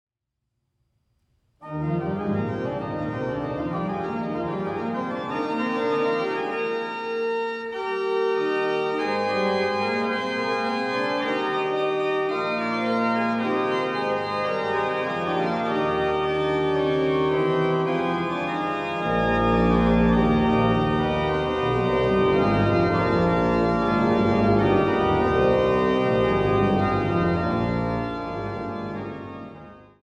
Stadtkirche St. Petri zu Löbejün